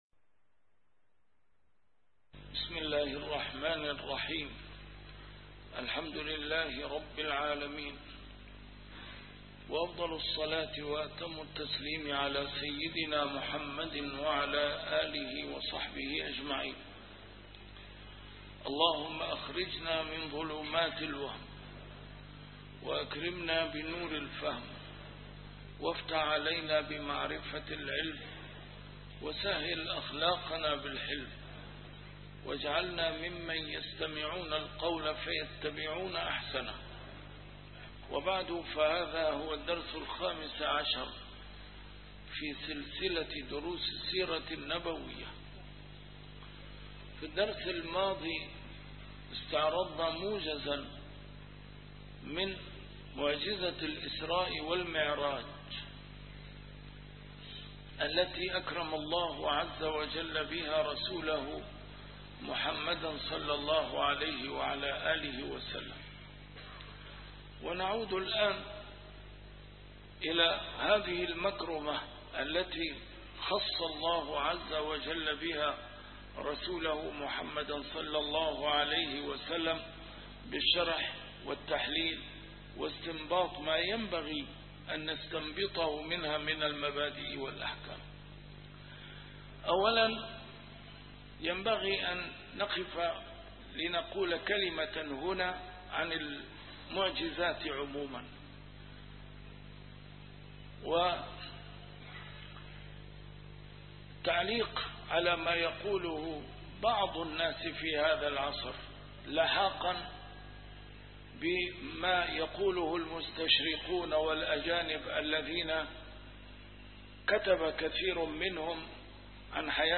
A MARTYR SCHOLAR: IMAM MUHAMMAD SAEED RAMADAN AL-BOUTI - الدروس العلمية - فقه السيرة النبوية - فقه السيرة / الدرس الخامس عشر : معجزة الإسراء والمعراج.. العبر والدلالات